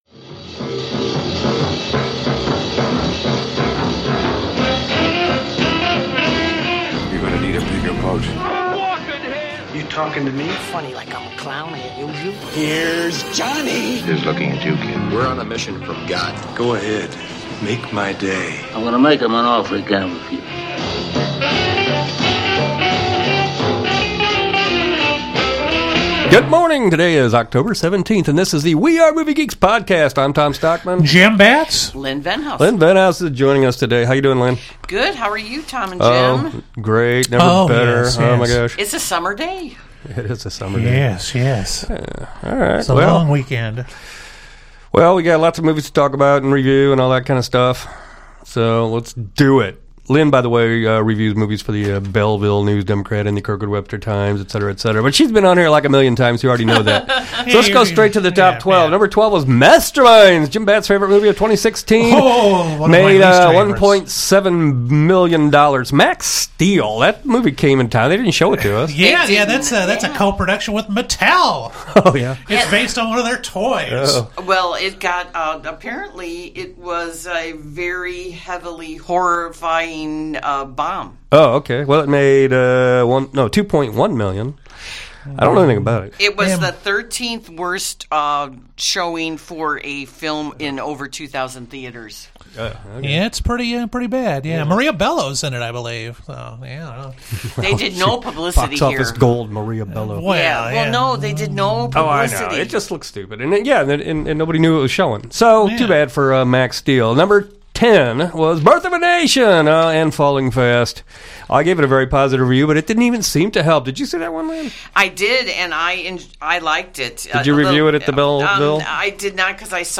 Our guest in the studio this week